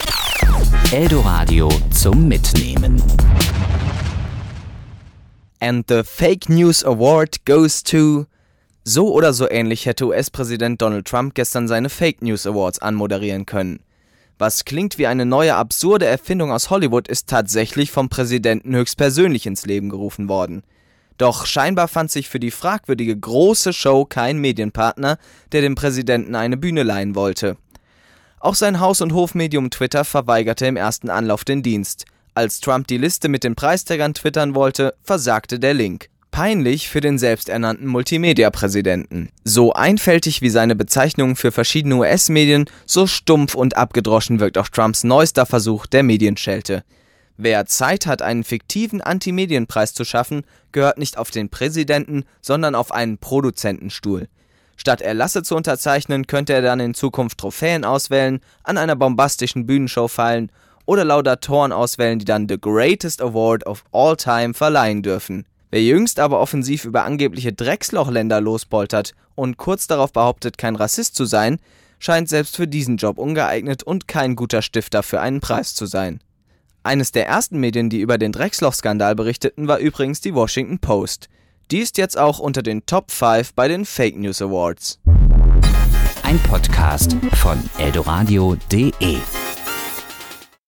Kommentar  Sendung